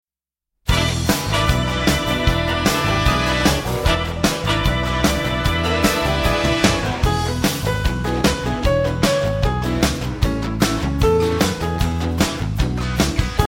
instrumental accompaniment music